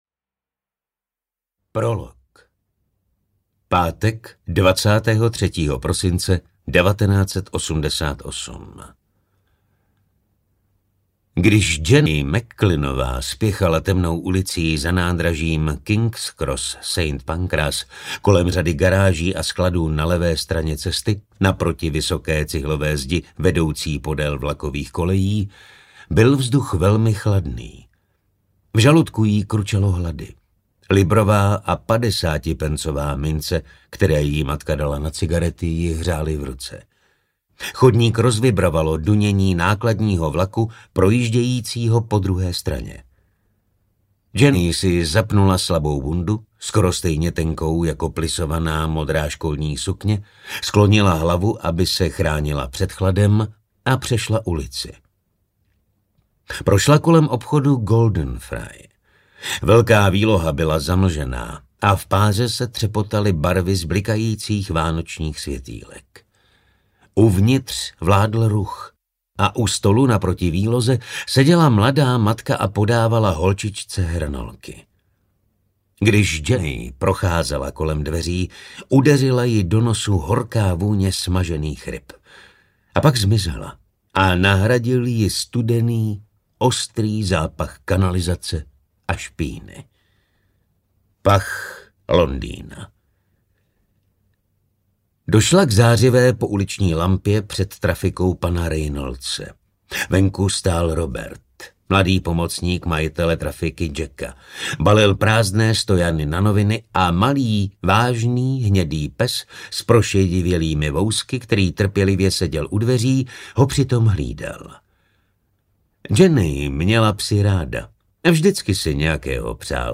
Záhadná oběť audiokniha
Ukázka z knihy